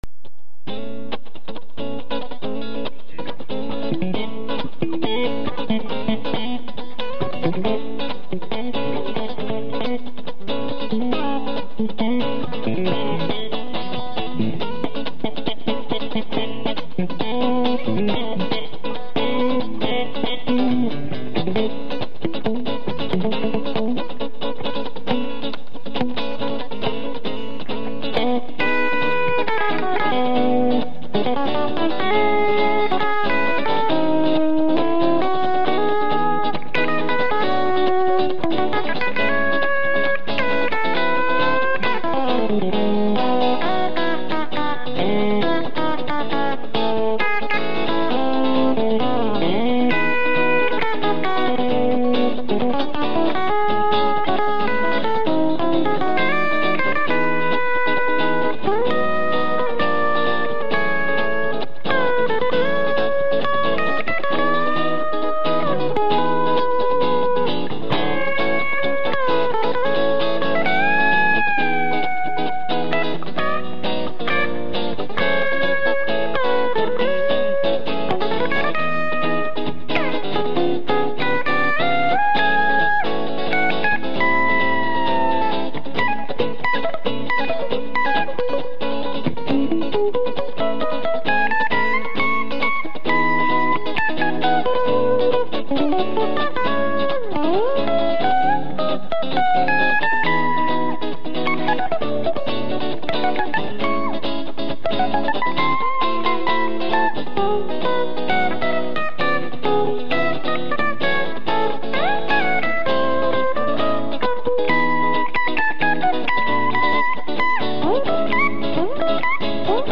신나는 분위기 ㅋㅋ 오랜만입니다..ㅠ 너무 바빠서..곤시잘 못찾앗는데..이제 시간적 여유가 생겨서..